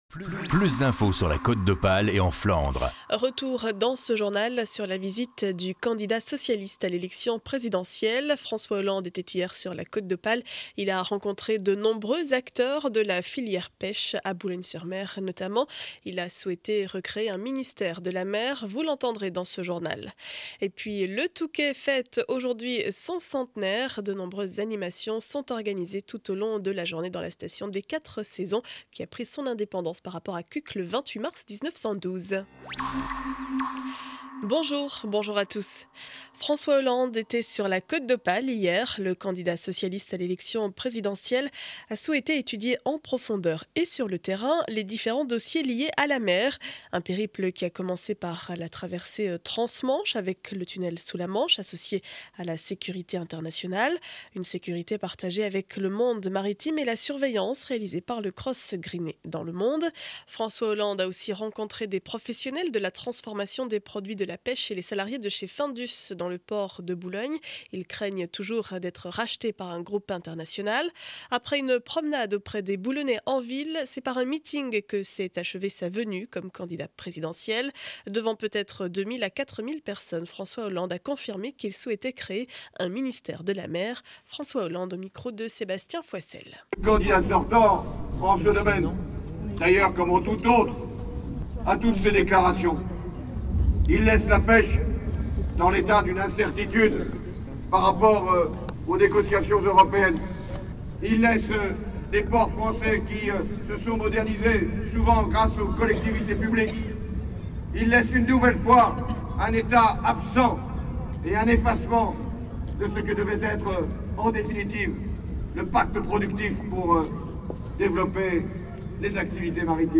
Journal du mercredi 28 mars 2012 7 heures 30 édition du Boulonnais.